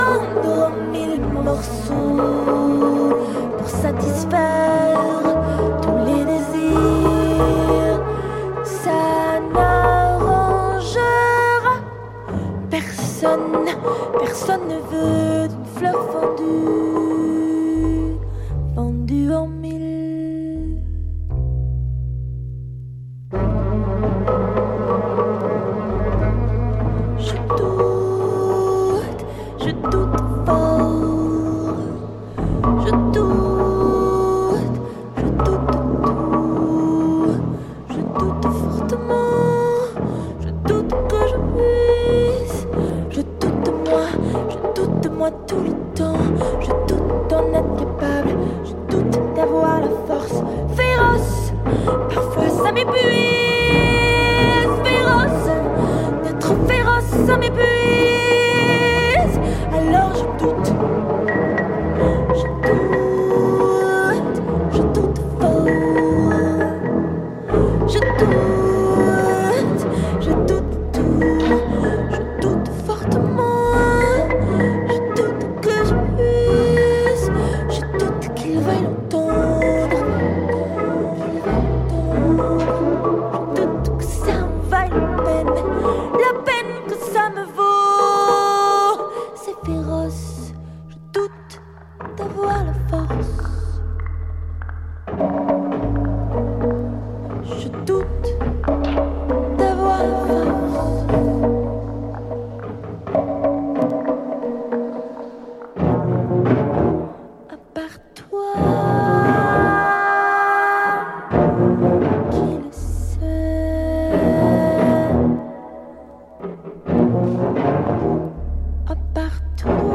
breathes a different kind of volatility
tuba
trombone